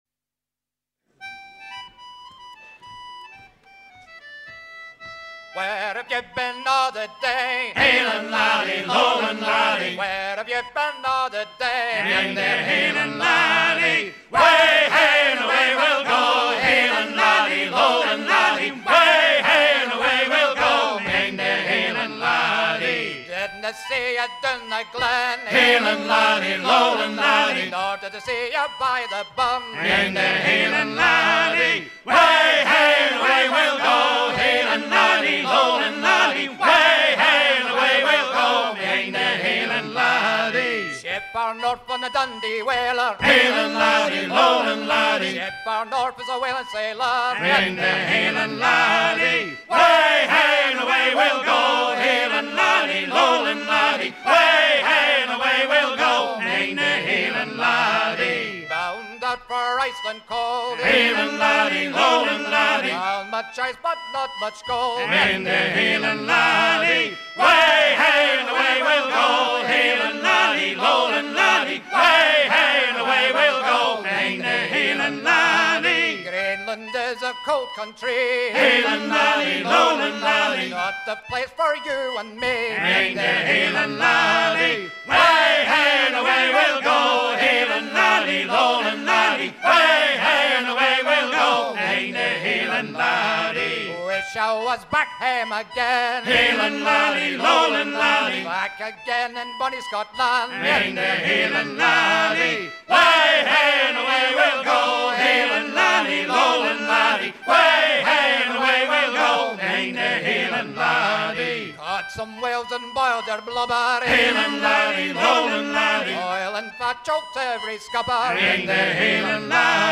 très ancien shanty
à hisser à courir